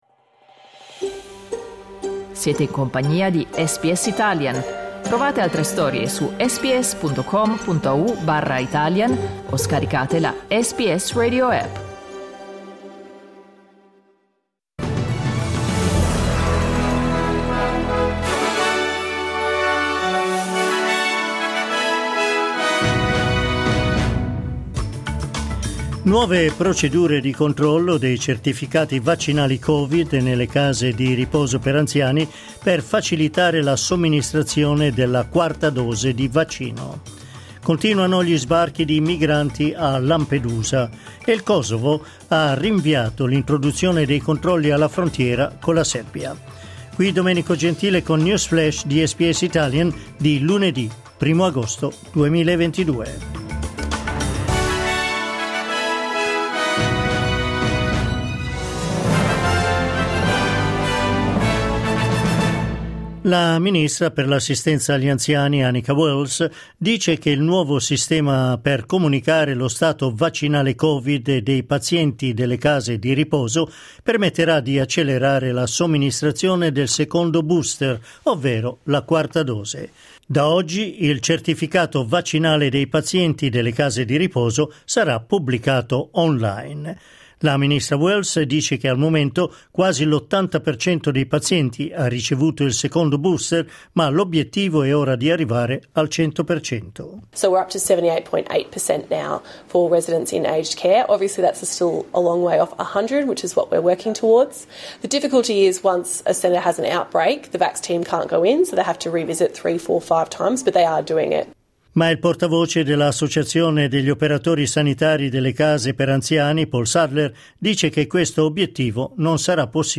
News flash lunedì 1 agosto 2022